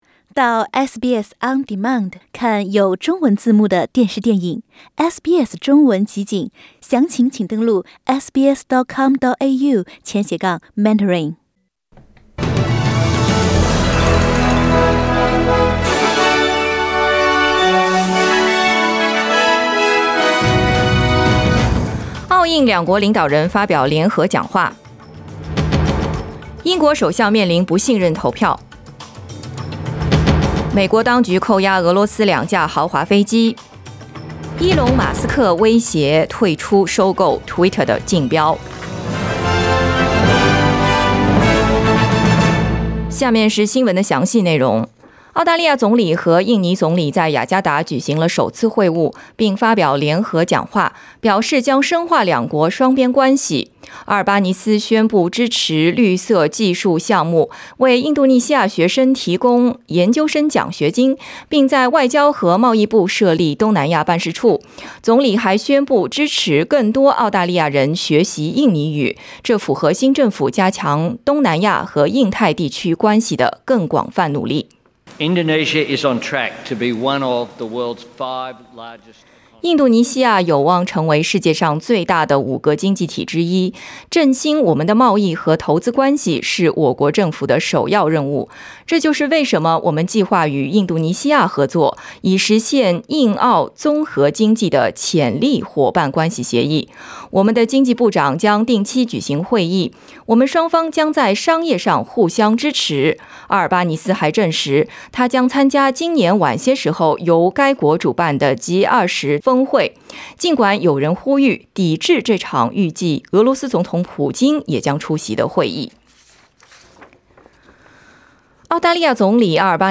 SBS早新闻 (2022年6月7日)